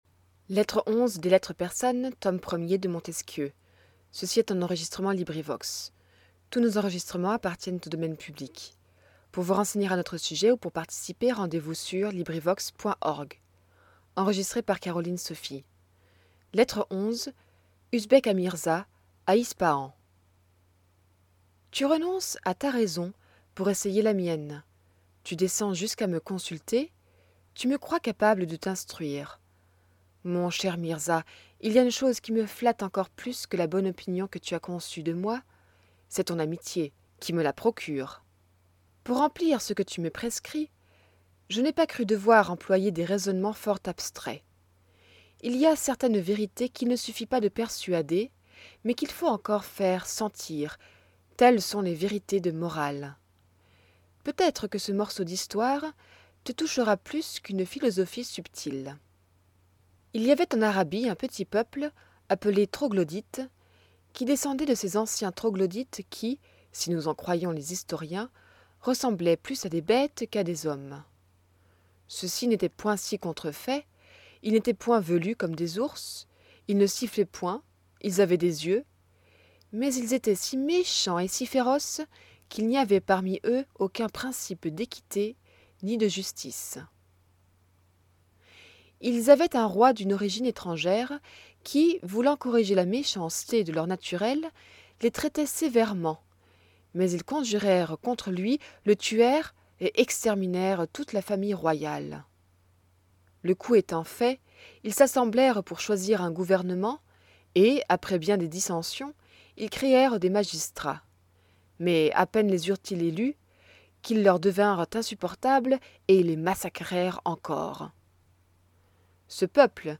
LibriVox recording by volunteers. Lettre 11. Usbek à Mirza, à Ispahan .
Enregistrement LibriVox par des bénévoles.